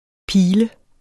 Udtale [ ˈpiːlə ]